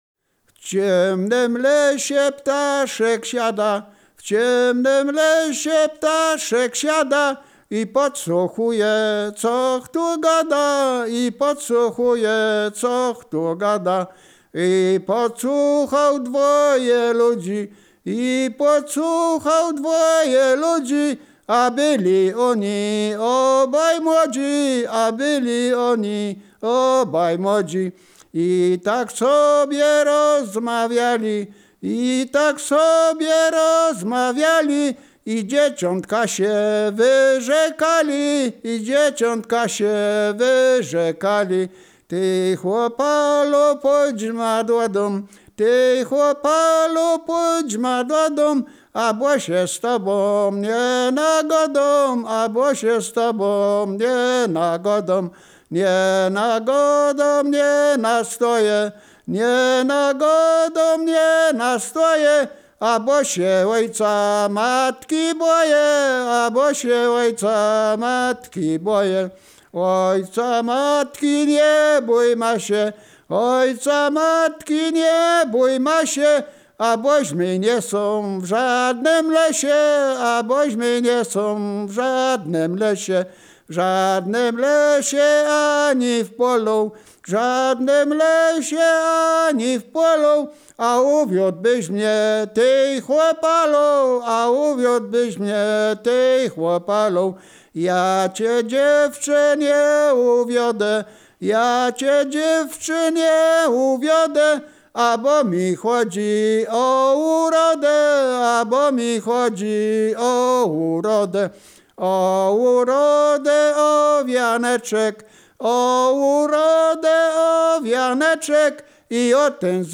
województwo wielkopolskie, powiat gostyński, gmina Krobia, wieś Stara Krobia
liryczne miłosne